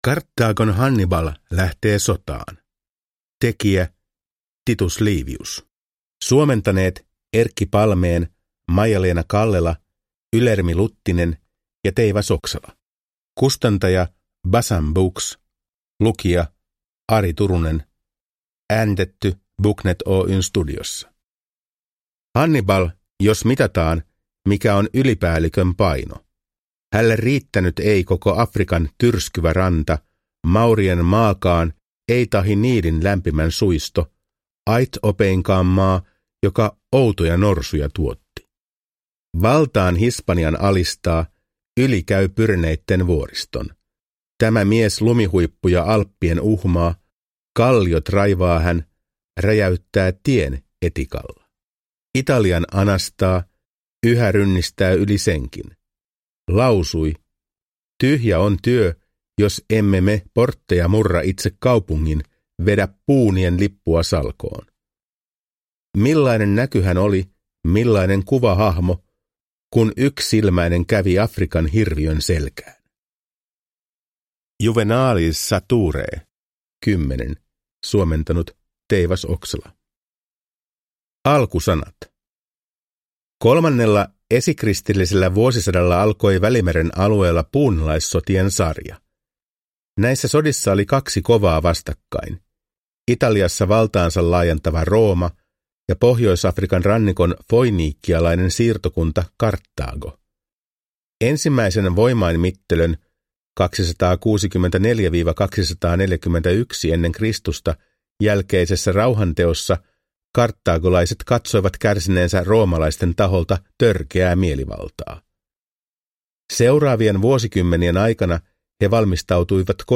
Karthagon Hannibal lähtee sotaan – Ljudbok